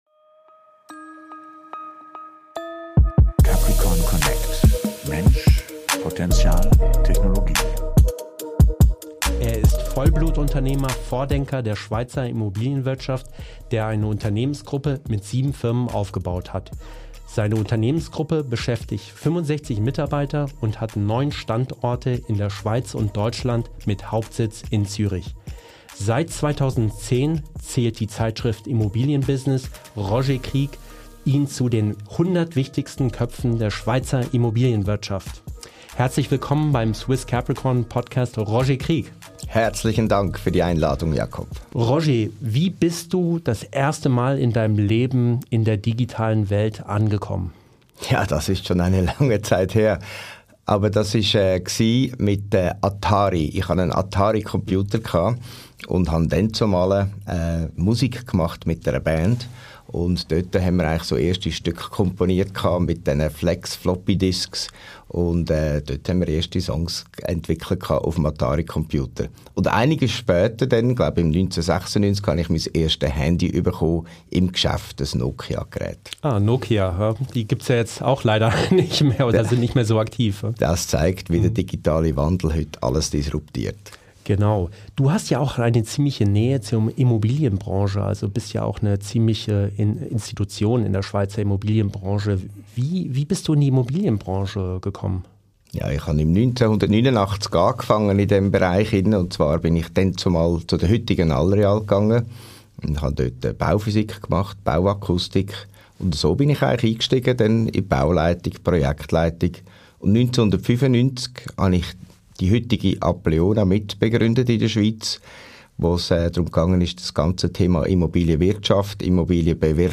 #56 - Interview